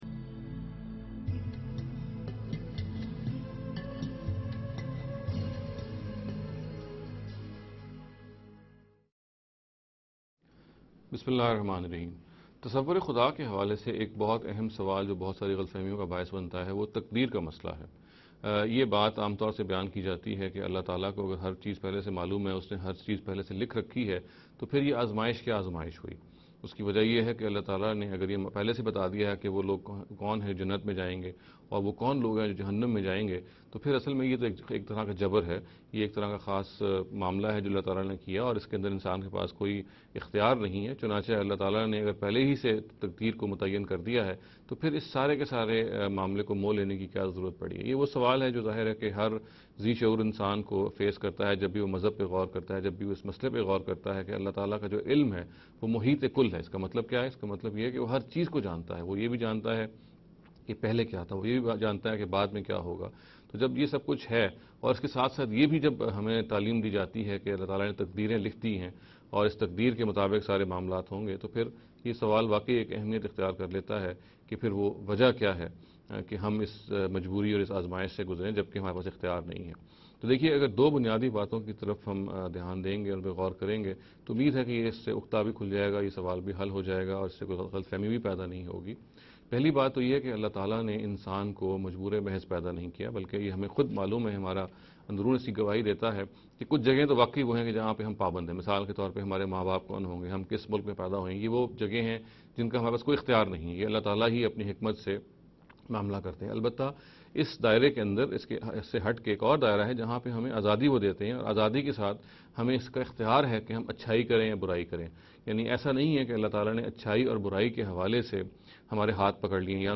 This lecture series will deal with some misconception regarding the concept of God.